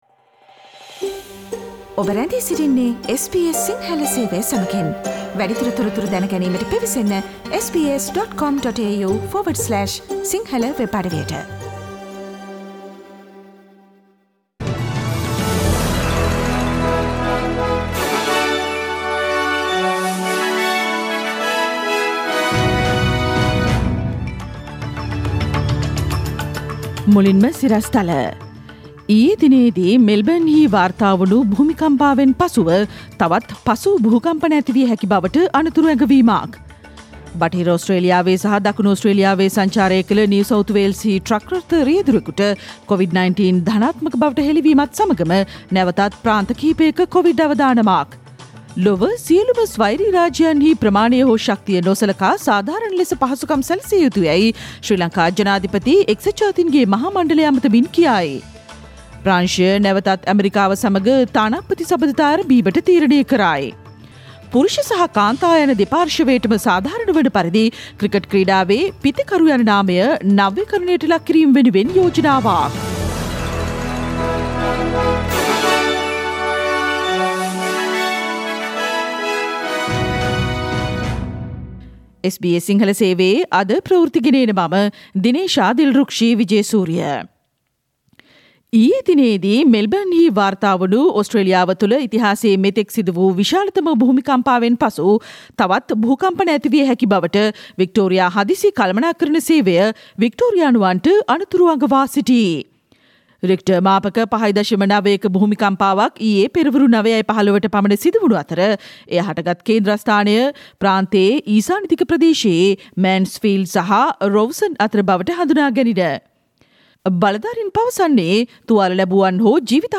ඕස්ට්‍රේලියාවේ අලුත්ම පුවත් වලට සිංහල භාෂාවෙන් සවන්දෙන්න SBS සිංහල සේවයේ දෛනික ප්‍රවෘත්ති ප්‍රකාශයට සවන්දෙන්න. SBS සිංහල පුවත් සඳුදා, අඟහරුවාදා, බ්‍රහස්පතින්දා සහ සිකුරාදා යන දිනවල පෙරවරු 11 ට බලාපොරොත්තු වන්න